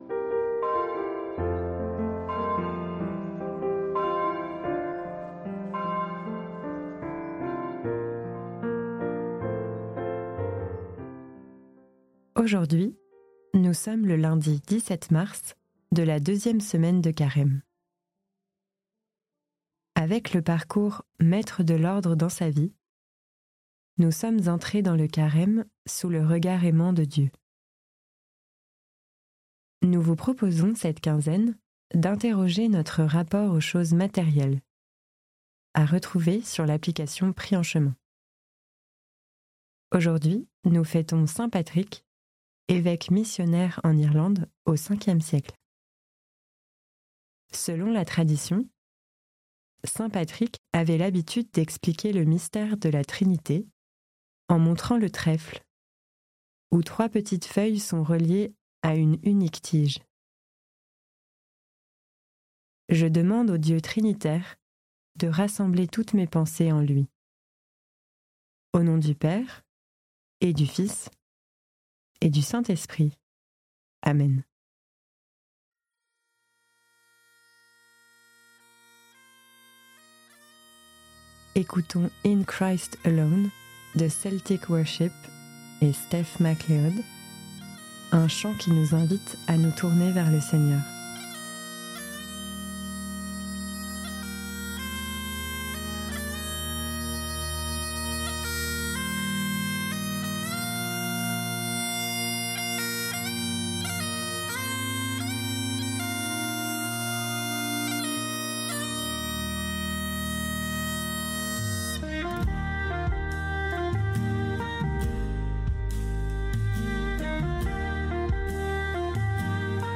Prière audio avec l'évangile du jour - Prie en Chemin
Prier et méditer avec l'évangile du jour, le psaume ou la lecture de la messe du jour selon la spiritualité de saint Ignace de Loyola.